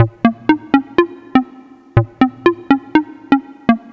Popcorn Bb 122.wav